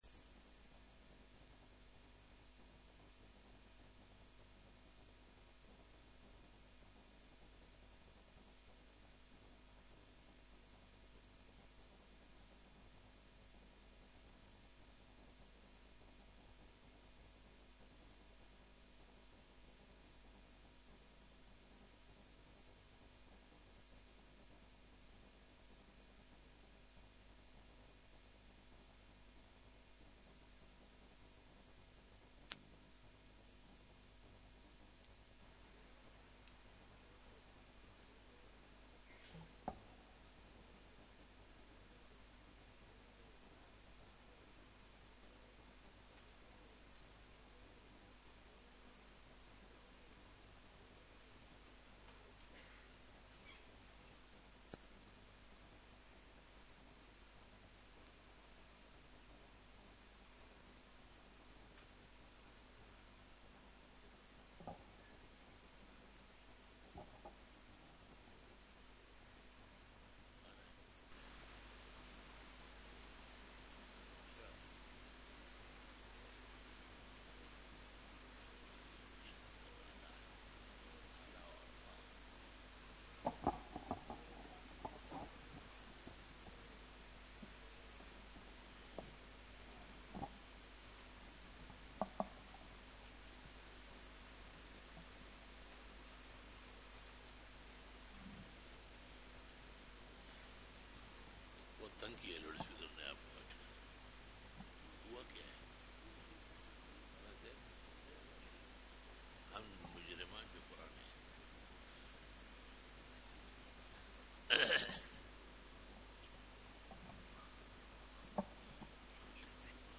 47 BAYAN E JUMA TUL MUBARAK (23 November 2018) (14 Rabi ul Awwal 1440H)